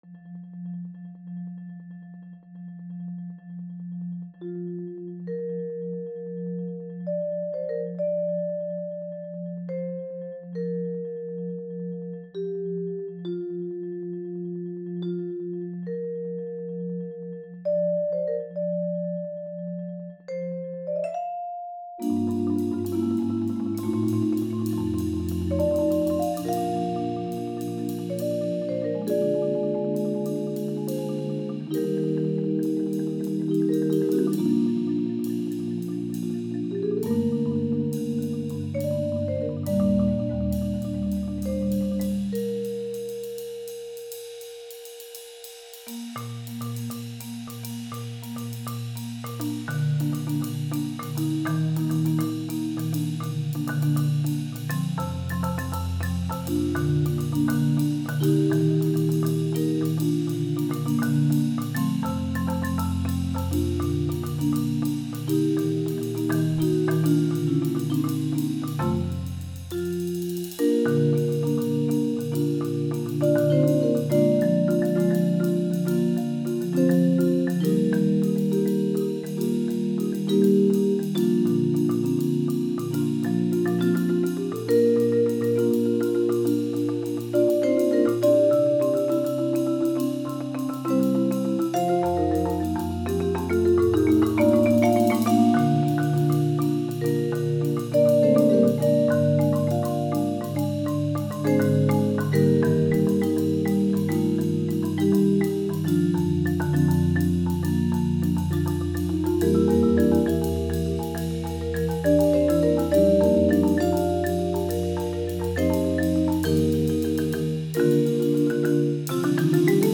Voicing: Percussion Quintet